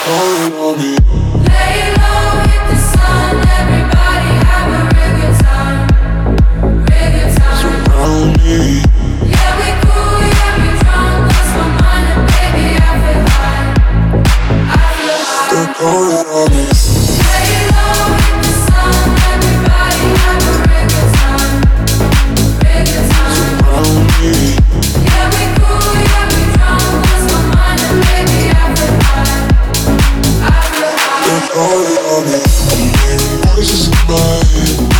Жанр: Танцевальные